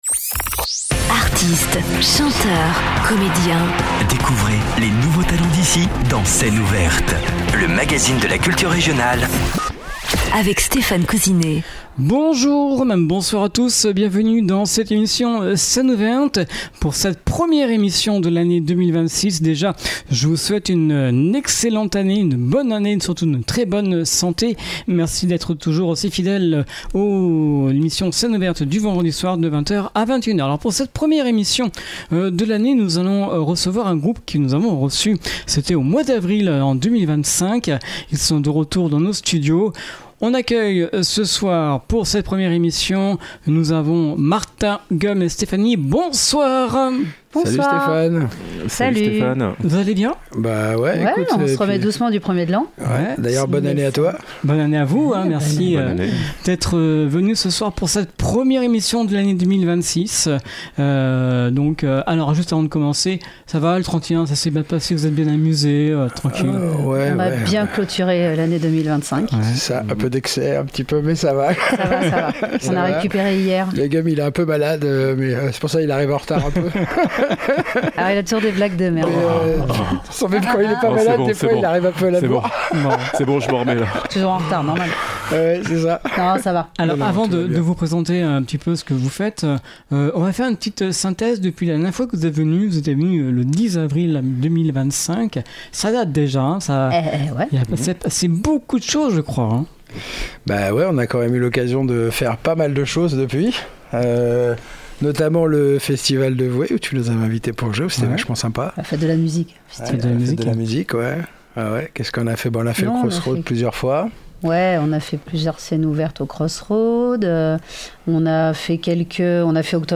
jazz, rock et chansons françaises